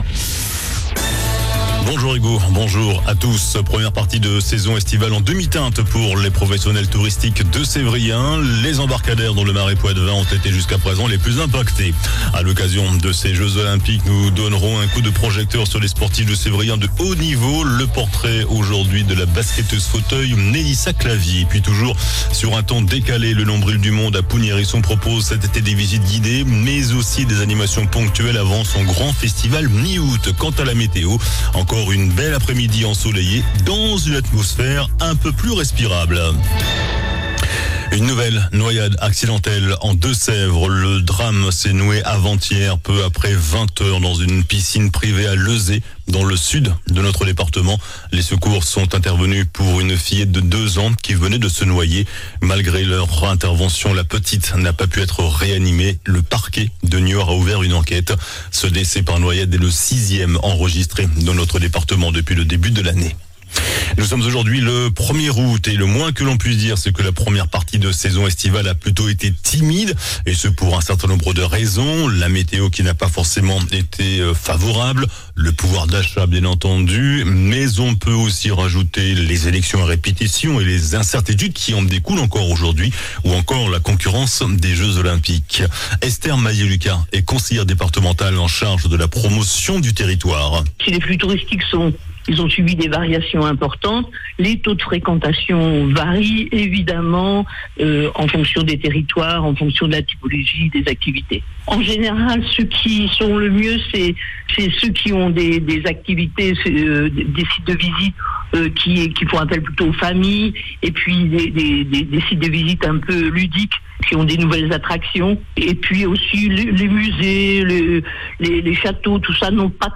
JOURNAL DU JEUDI 01 AOUT ( MIDI )